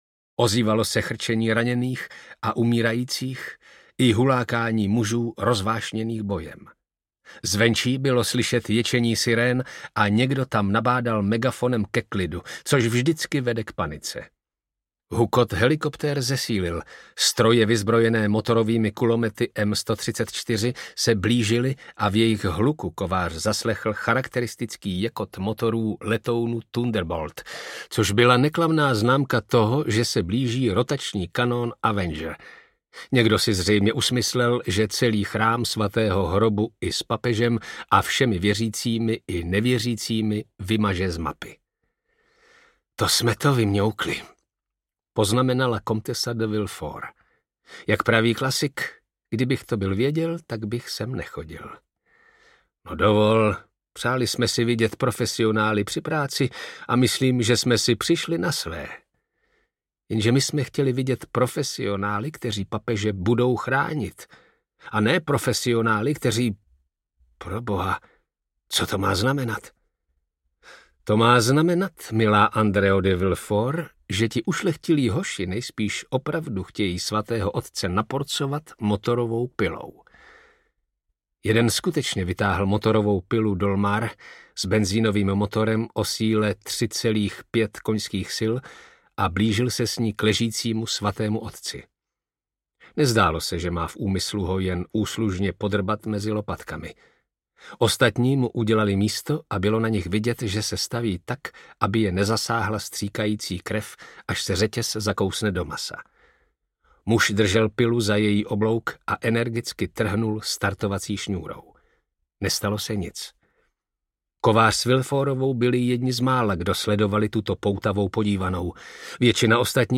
Jeruzalémský masakr audiokniha
Ukázka z knihy
jeruzalemsky-masakr-audiokniha